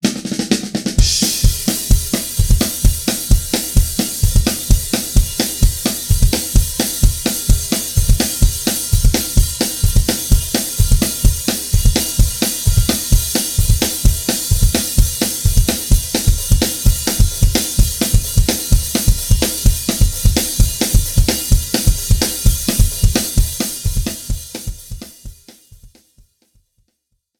Besetzung: Schlagzeug
09 - Punkrock-Beats 1
Punkrock-Beats